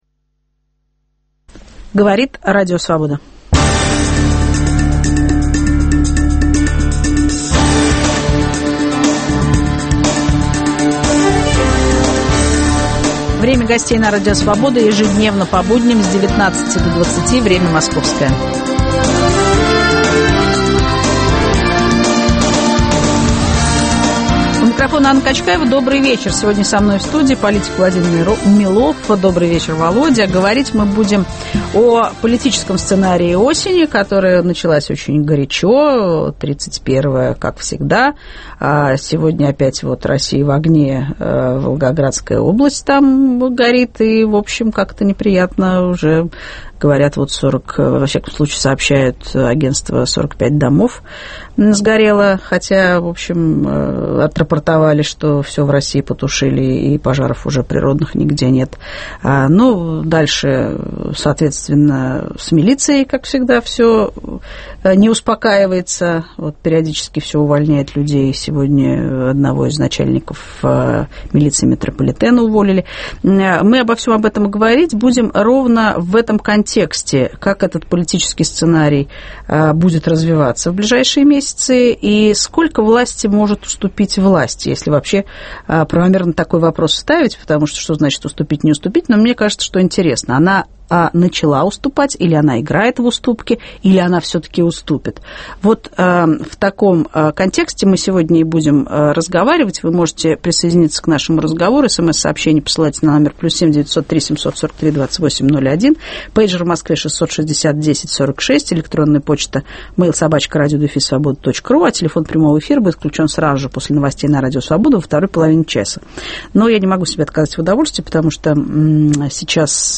Сколько власти может уступить власть? В студии - политик Владимир Милов.